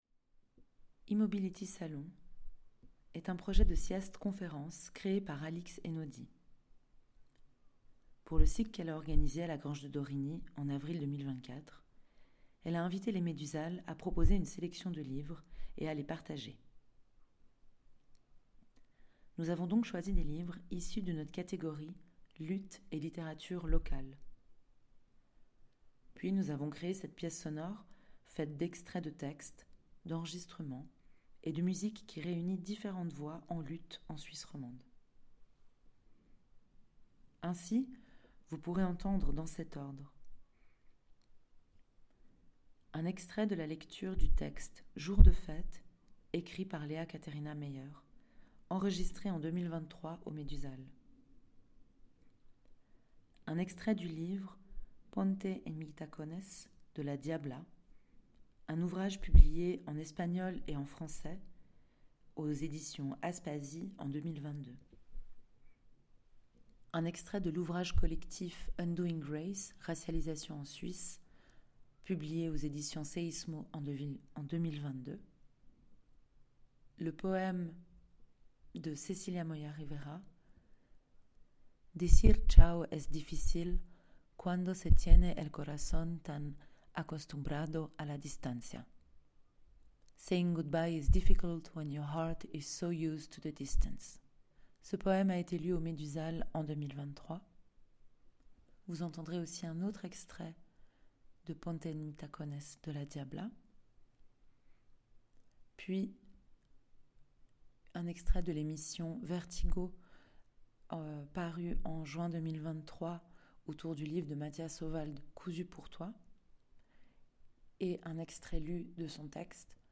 lecture des Médusales à la Grange de Dorigny à Lausanne
montage_piece_sonore_finale_site.mp3